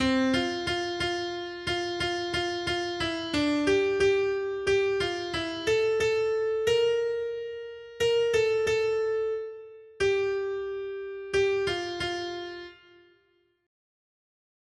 Noty Štítky, zpěvníky ol345.pdf responsoriální žalm Žaltář (Olejník) 345 Skrýt akordy R: Drahocenná je v Hospodinových očích smrt jeho zbožných. 1.